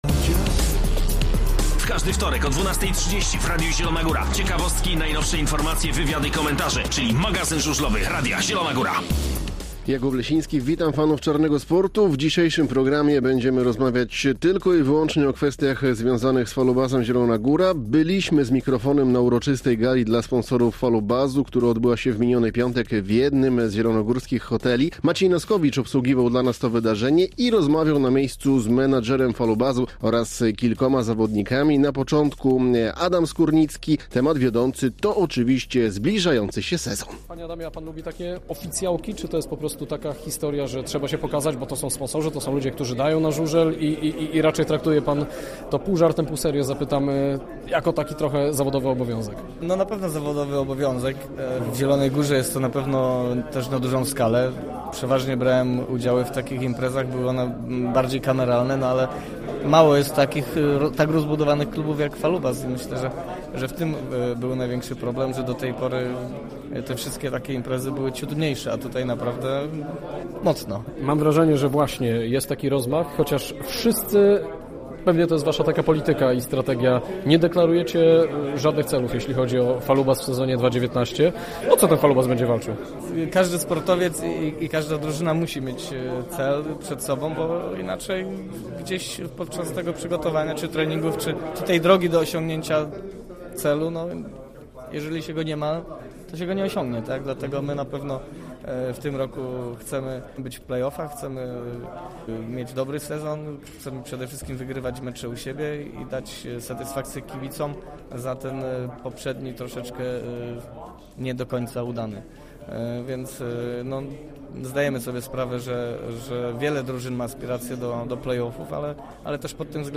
Zapraszamy na magazyn żużlowy Radia Zielona Góra.
Byliśmy z mikrofonem na uroczystej Gali dla Sponsorów Falubazu, która odbyła się w miniony piątek w jednym z zielonogórskich hoteli.